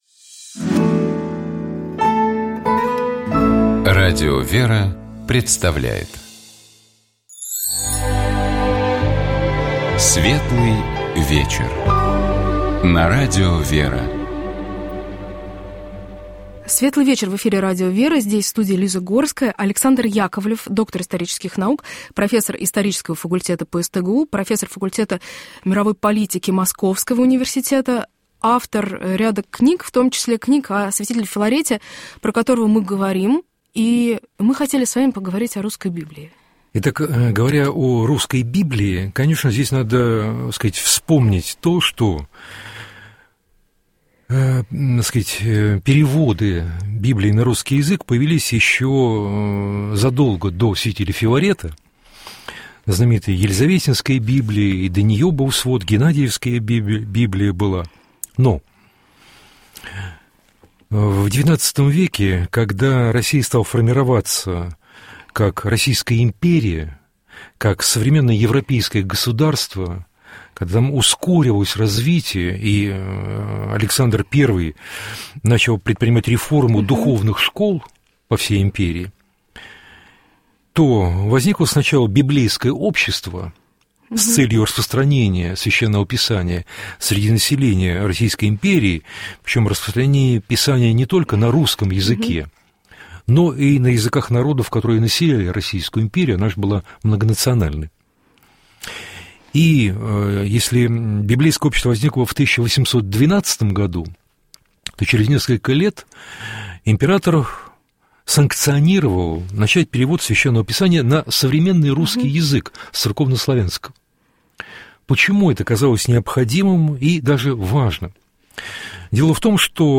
Здесь с вами в студии